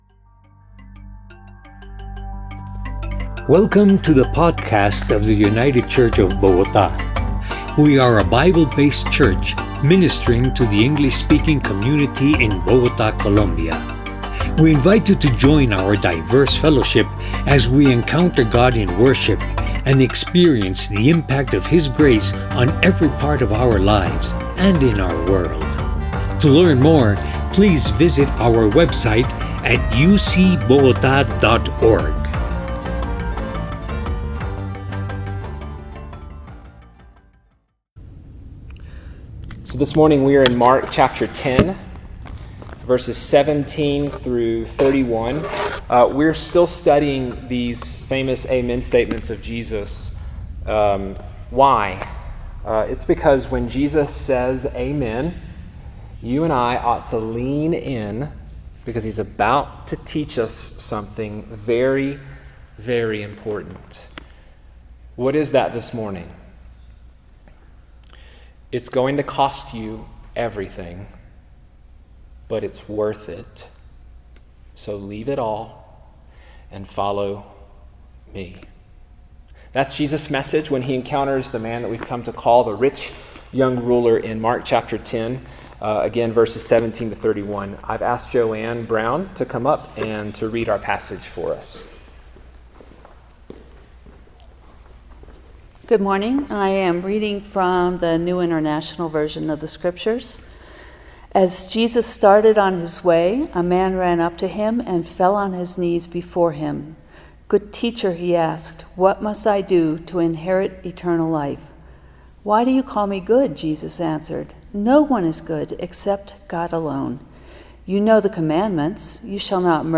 – United Church of Bogotá